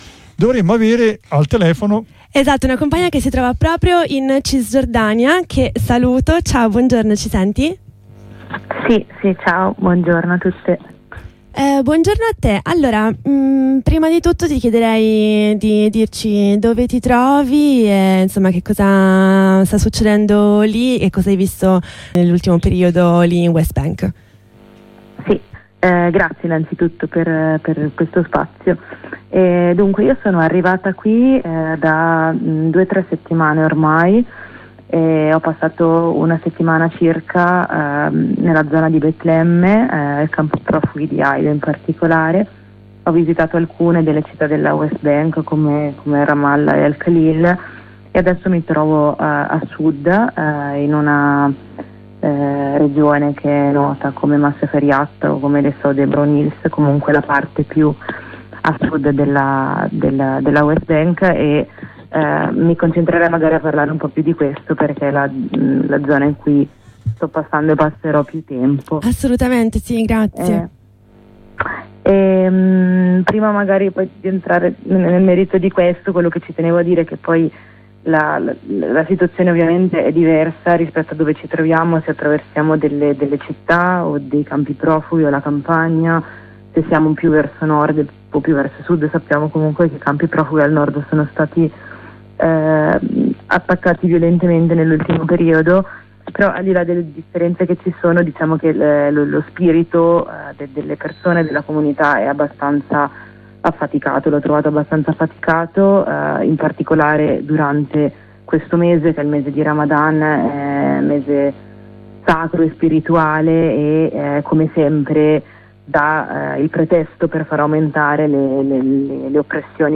Abbiamo chiesto a una compagna che si trova attualmente nella zona di Masafer Yatta di raccontarci cosa sta avvenendo in Cisgiordania. Ascolta la corrispondenza.